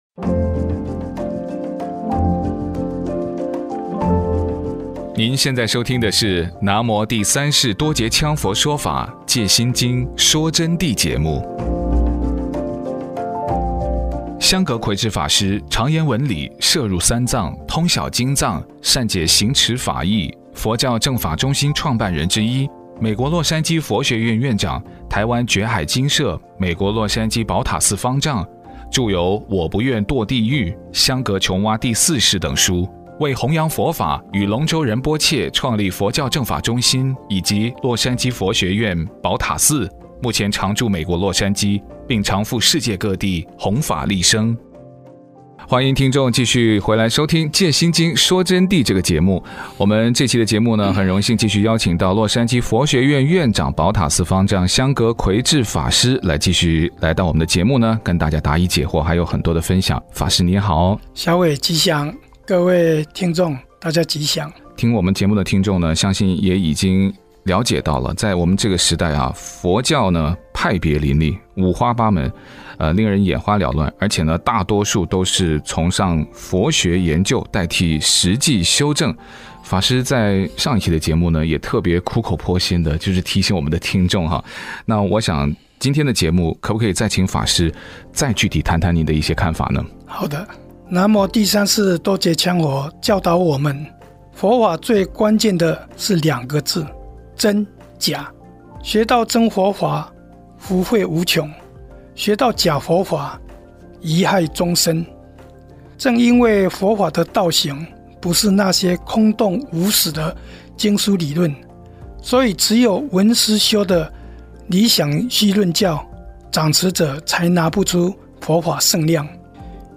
▲佛弟子访谈（二十五）一月十九佛陀日的因缘和如何脱离因果束缚解脱轮回？